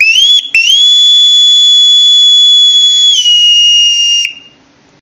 Boatswain's Call
The notes on the score start from low and shoots up to high in a smooth line with a sharp finish, then back to low and rapidly up to high for 3 seconds on a all-smooth line before dropping quickly to low.
General Call Soundbyte